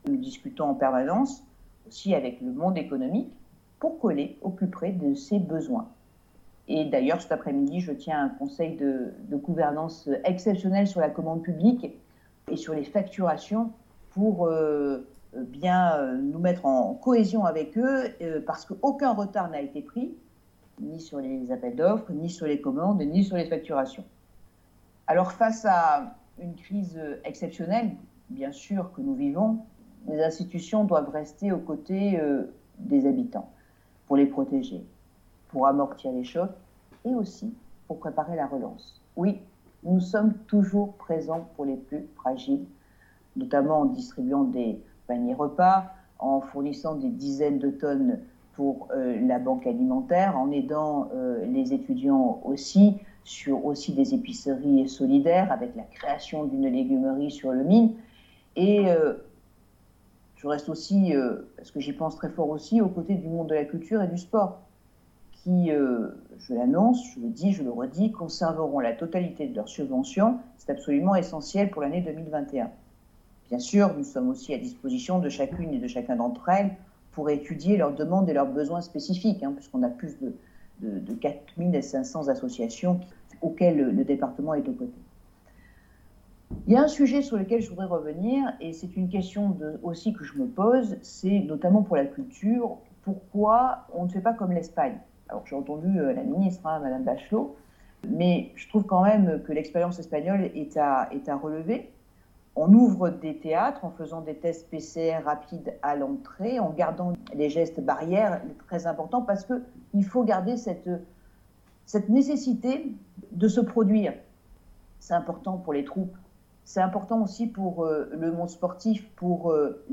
Vœux à la presse de Martine Vassal : -Nous avons réalisé des promesses des années 70-